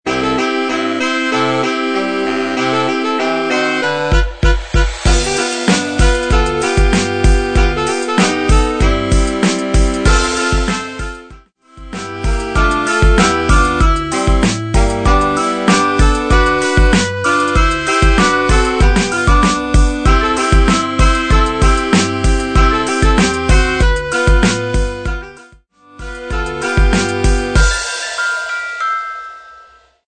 96 BPM
Christmas
Urban